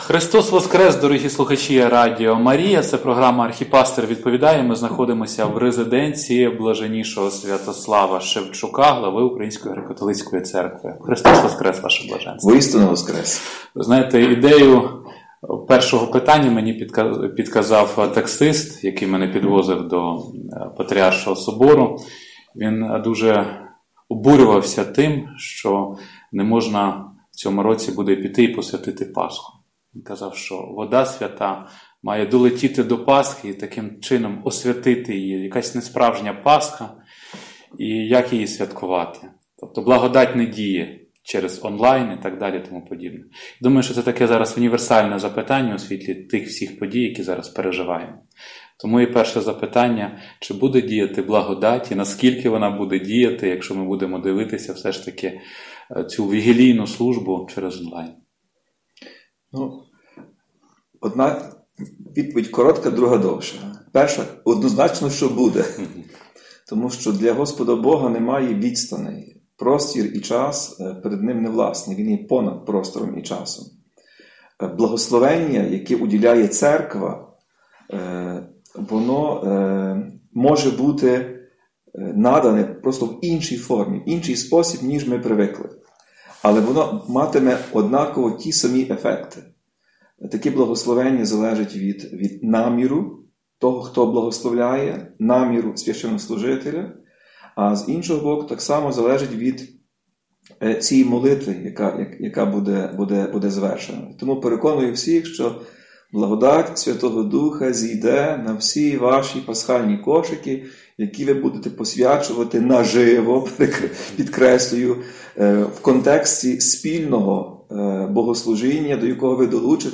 Таку думку висловив Отець і Глава Української Греко-Католицької Церкви Блаженніший Святослав в ефірі радіо «Марія», відповідаючи на запитання про те, чи скоро апокаліпсис?